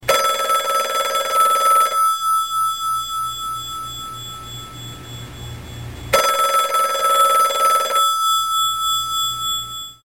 Android, Klassisk Telefon, Klassisk